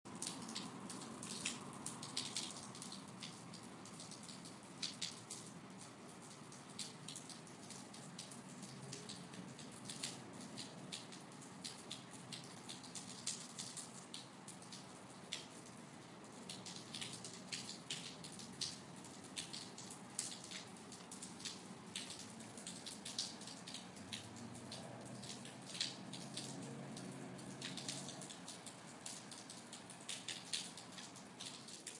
天气 " Rain30s
描述：一个可循环的30秒的雨滴落在平屋顶的样本。这本身是由一个较短的样本循环而成，然后将其切割，以掩盖明显的可检测的重复模式。一个振幅包络被应用，使结束时的音量与开始时的音量一致。
标签： 环境 湿 氛围 下雨 天气 浇注
声道立体声